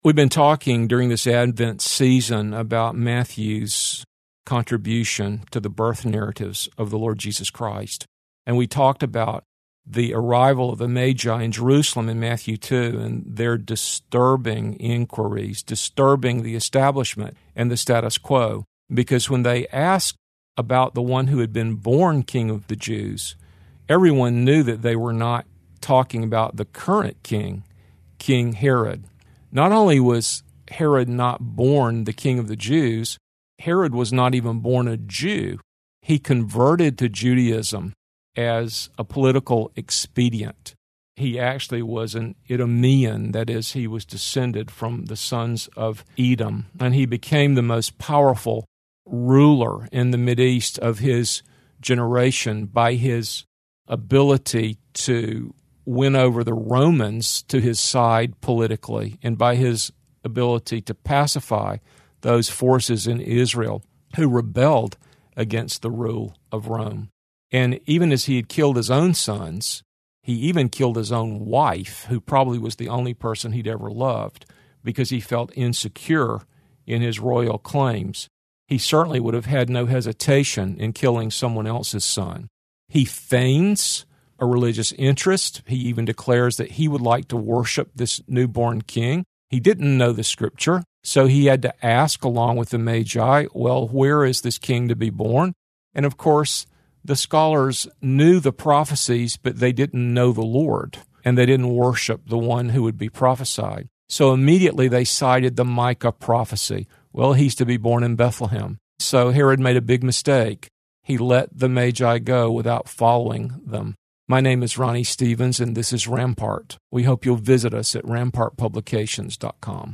two minute radio broadcasts